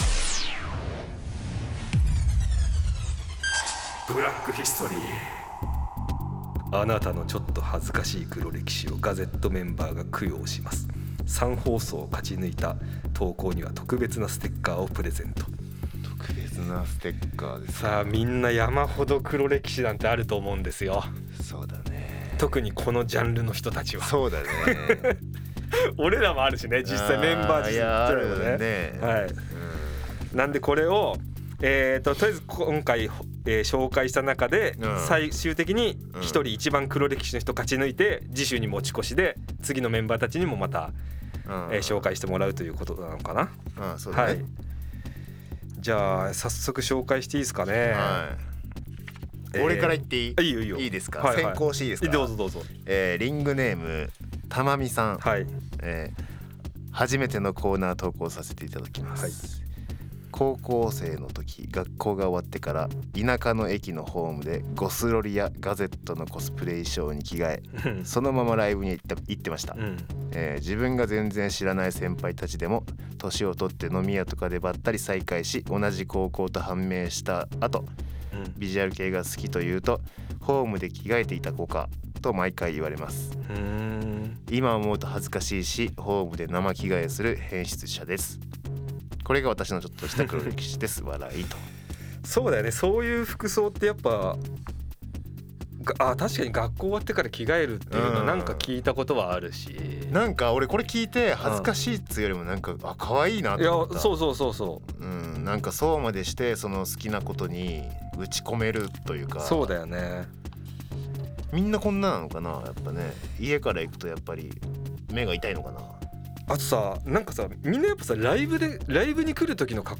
the GazettE popular radio program「RADIO JACK」is back just for a HERESY member!On this program, the band member will be on talk as radio personality every other Friday.